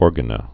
(ôrgə-nə)